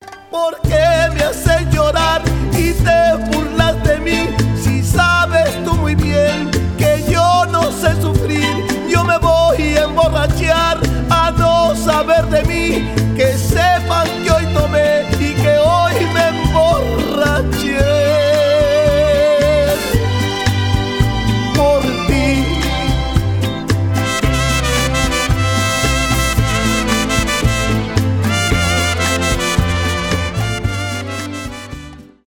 инструментальные , танцевальные